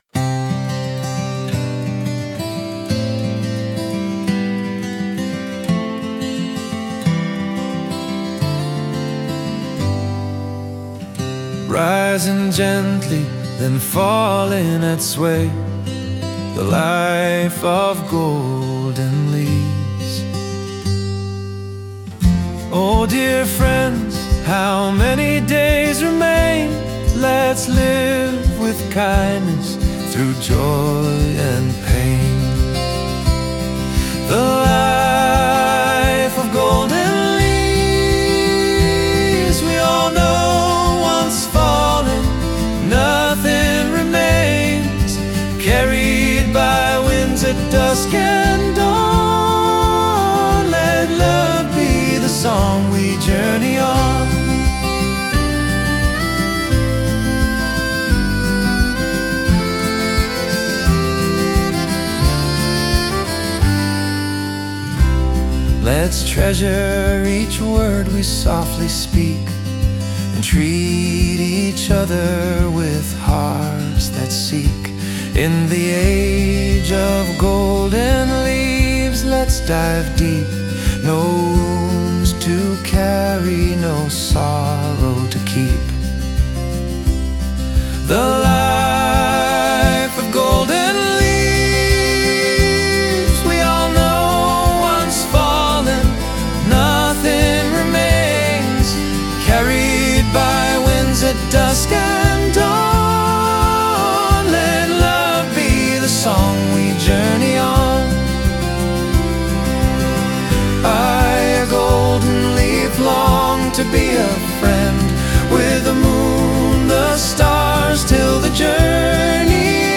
Final Chorus (softer, reflective)
This is in a folk/acoustic style (easy to sing & play on guitar or piano). Key: C Major (bright, natural feel).